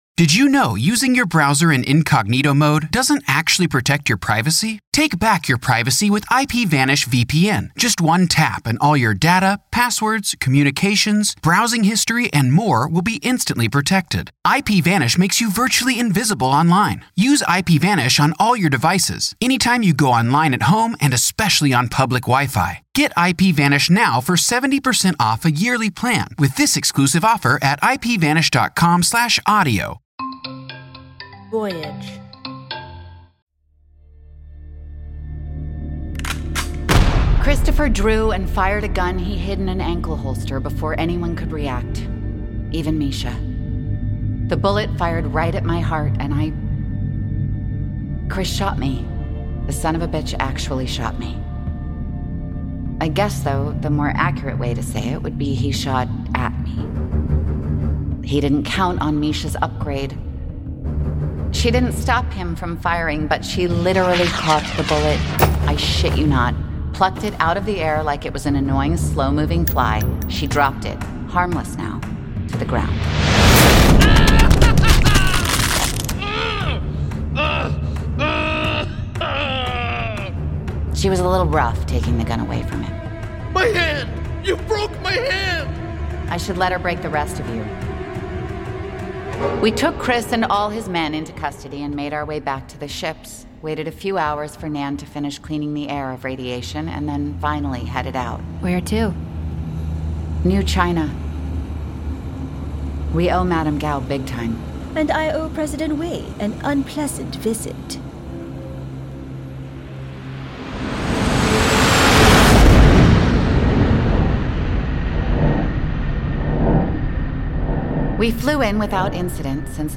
Starring Lily Rabe, as Reya.